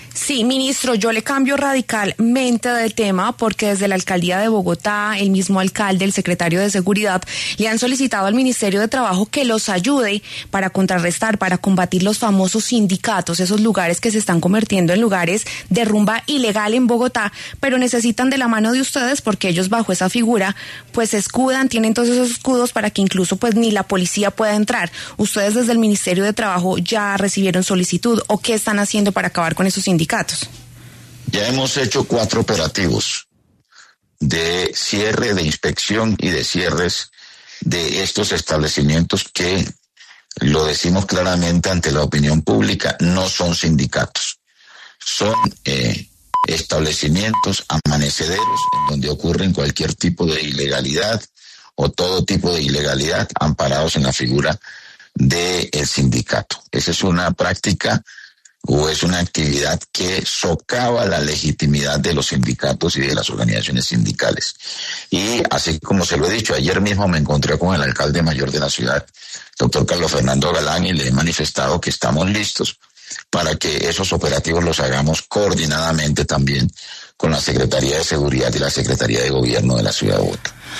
En los micrófonos de W Radio, el ministro de Trabajo, Antonio Sanguino, confirmó que en los últimos días se han cerrado cuatro establecimientos en Bogotá que usaban la fachada de sindicato para incumplir con la normativa sobre los horarios de rumba en la capital que lo establecen hasta las 3 a.m.